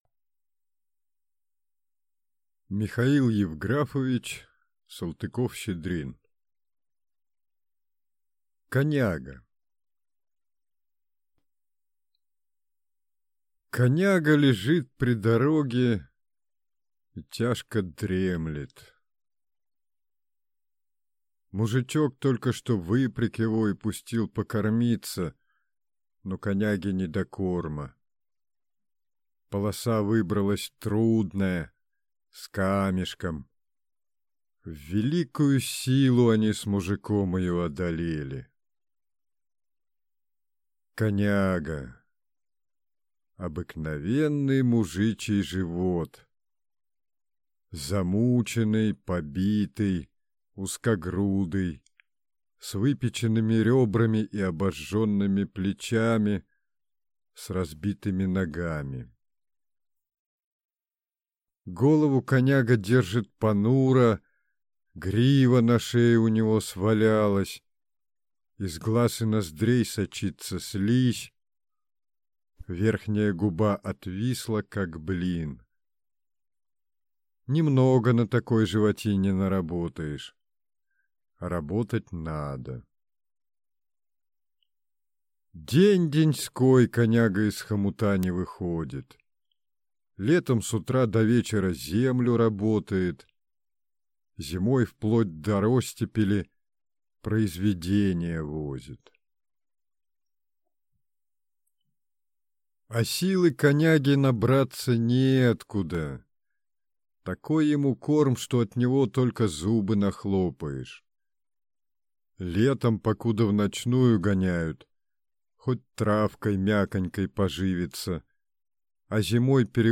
Аудиокнига Коняга | Библиотека аудиокниг